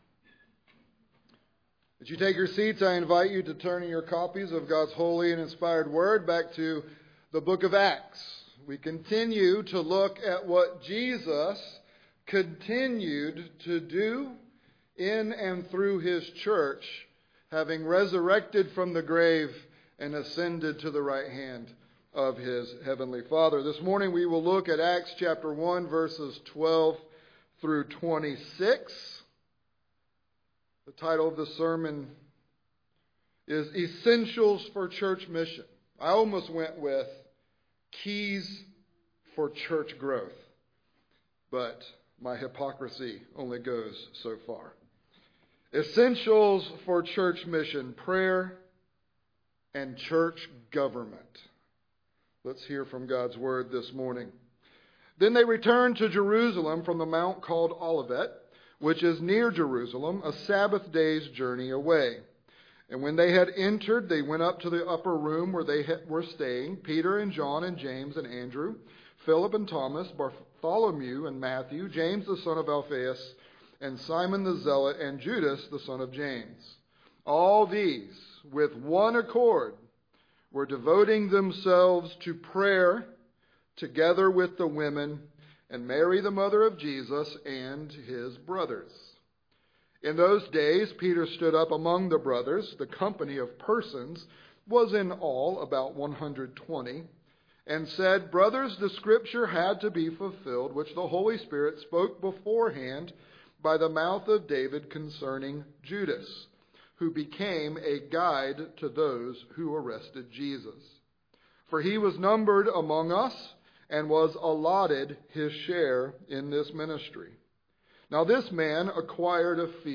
Sermons from Grace Covenant Church: Dallas, GA